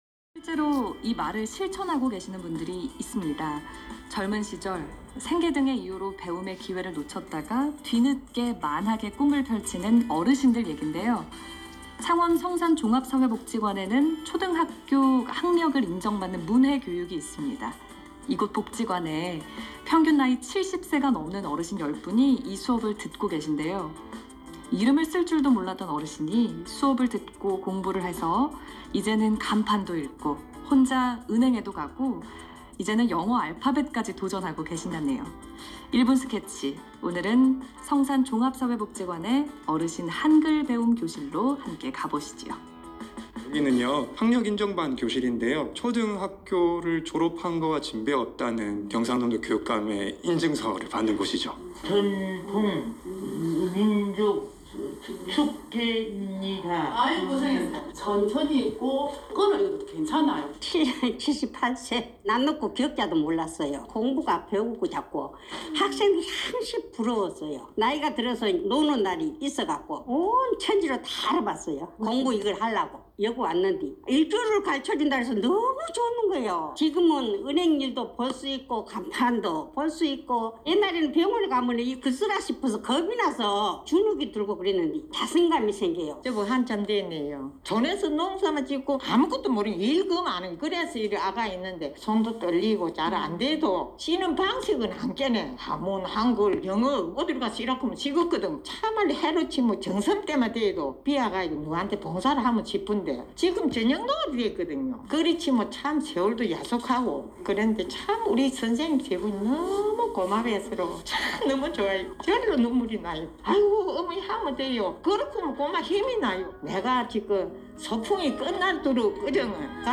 금일 현장 소리와 인터뷰 내용은 2021년 10월 27일 수요일 17:40~17:45 사이 KBS 창원 1라디오 주파수 91.7MH에서 들으실 수 있을 예정입니다.